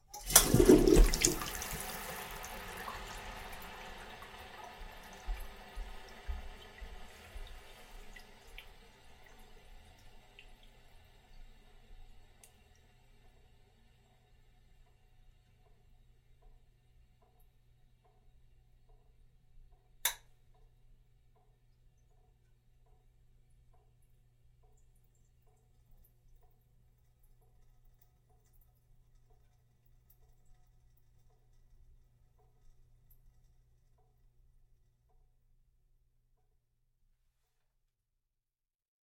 随机应变 " 马桶冲水顺畅 好的水细节 水箱补水嘶嘶声
描述：厕所冲洗光滑好水细节坦克填充hiss.flac
标签： 填充 冲洗 细节 流畅 嘶嘶声 坦克 不错 厕所
声道立体声